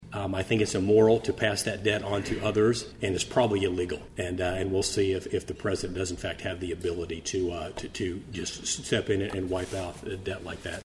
1st District Congressman Tracey Mann was the featured guest at the Manhattan Area Chamber of Commerce Military Relations Committee Luncheon, held Wednesday at the Manhattan Conference Center.